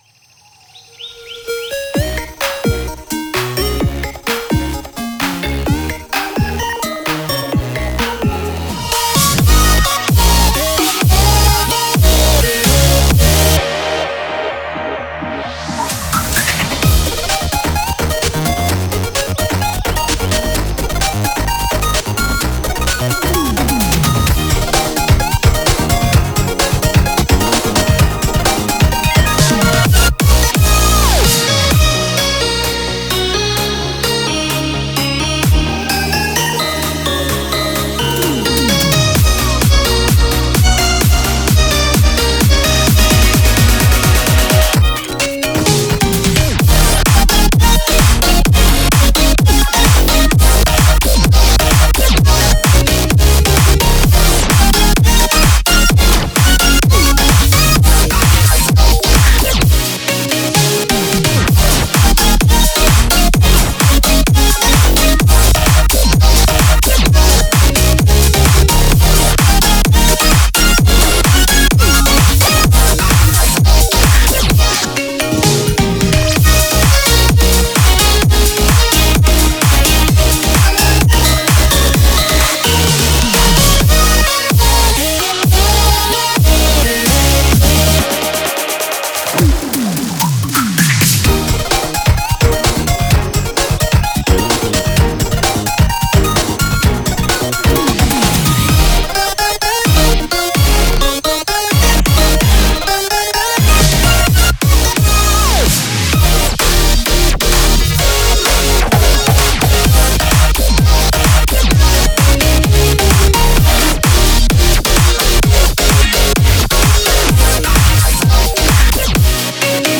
BPM129
Audio QualityPerfect (High Quality)
128BPM complextro song